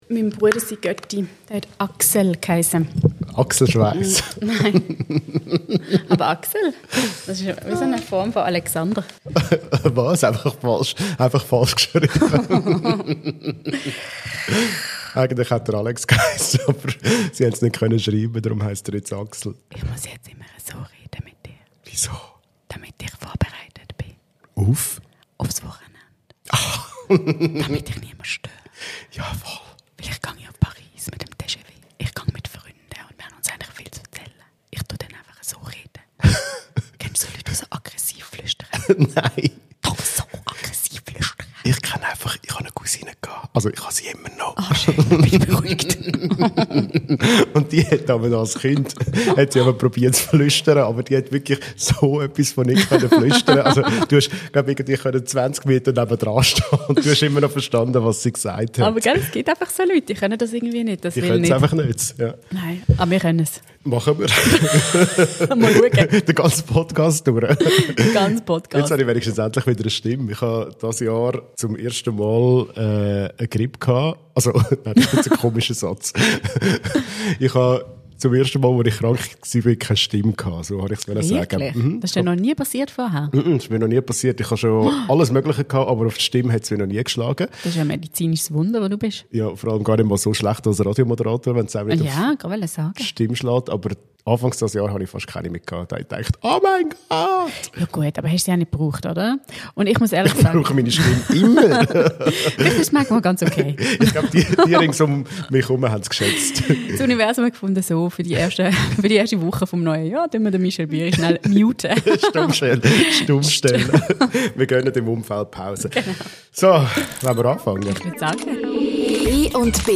Im Hier und Jetzt beschäftigen ihn dafür eine angeschlagene Stimme und die Frage, ob man sich wirklich 250 Namen merken kann.